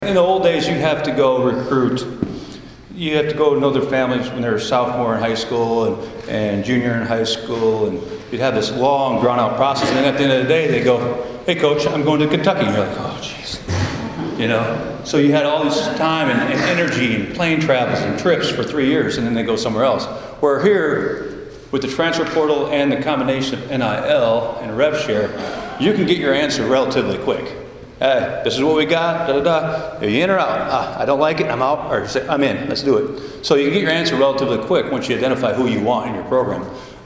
talks basketball to Hopkinsville Kiwanis Club